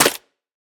Minecraft Version Minecraft Version snapshot Latest Release | Latest Snapshot snapshot / assets / minecraft / sounds / block / muddy_mangrove_roots / step6.ogg Compare With Compare With Latest Release | Latest Snapshot
step6.ogg